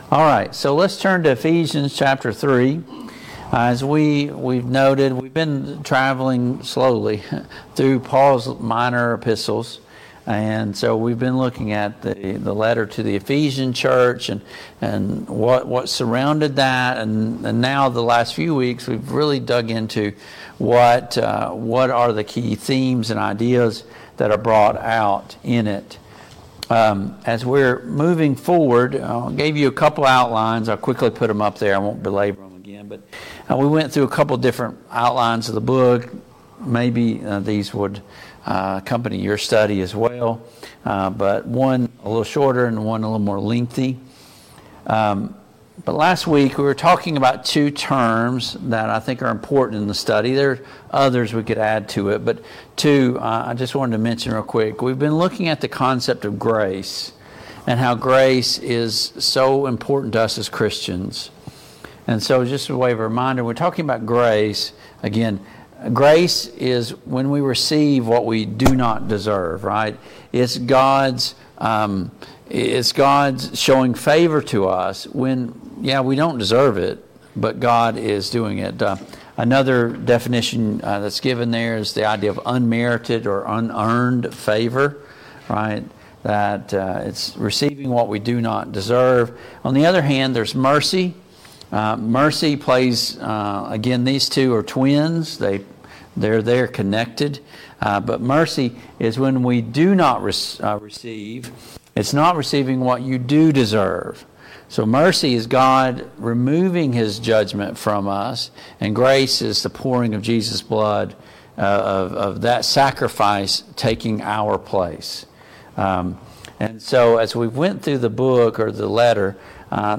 Passage: Ephesians 3 Service Type: Mid-Week Bible Study Download Files Notes « 20.